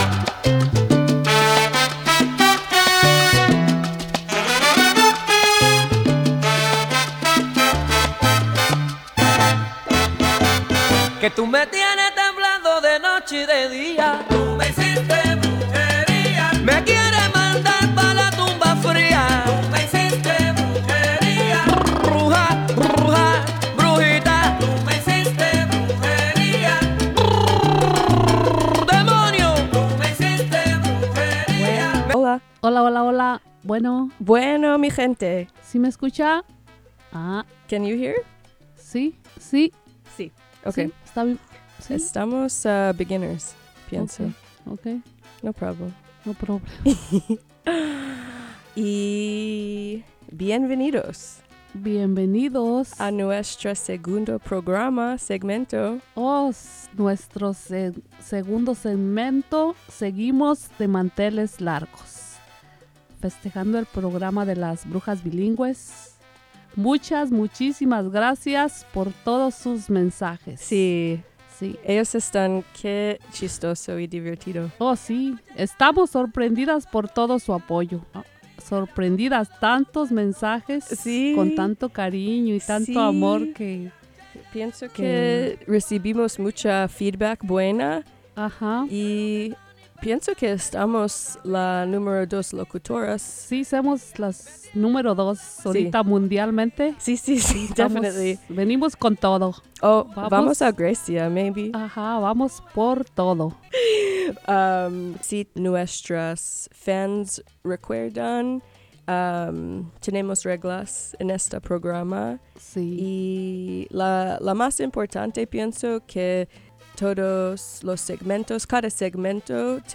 Las Brujas dedicate this segment to all the farm workers and ranchers working outside during this crazy summer! 2 requests, 2 songs, 2 much fun!